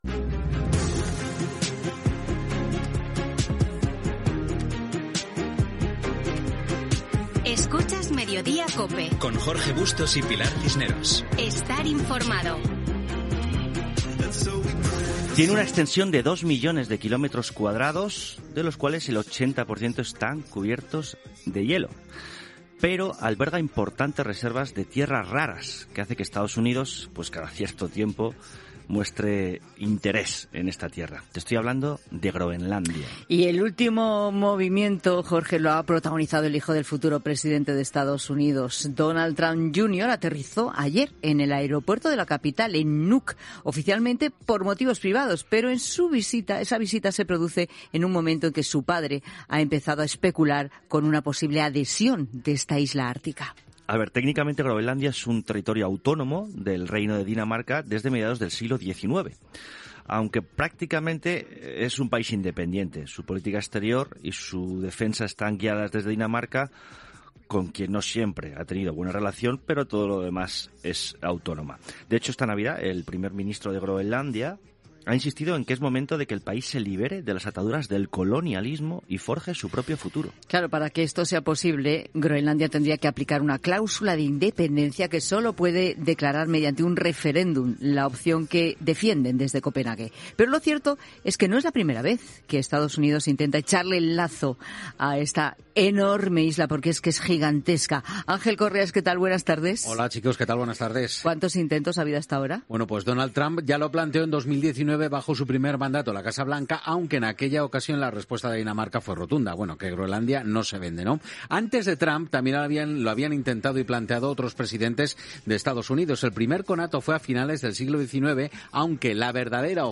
Indicatiu del programa, comentaris sobre la visita del fill de Donald Trump a Groenlàndia. Gènere radiofònic Info-entreteniment